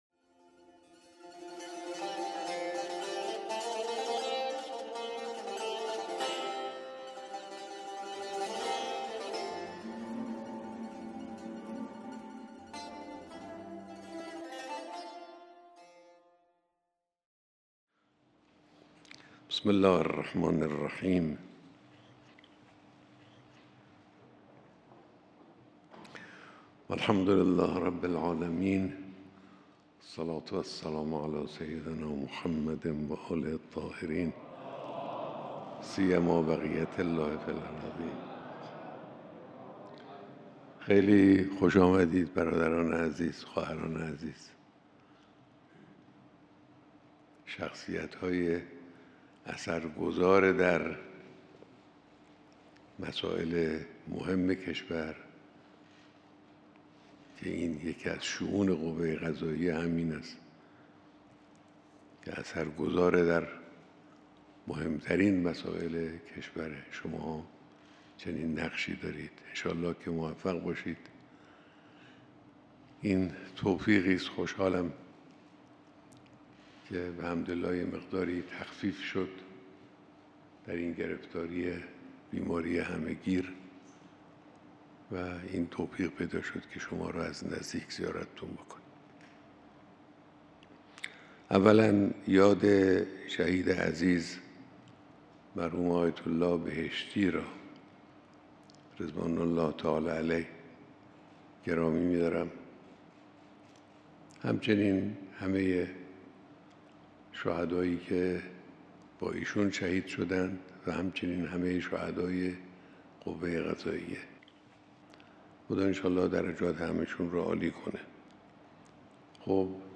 بیانات در دیدار رئیس، مسئولان و جمعی از کارکنان قوه قضاییه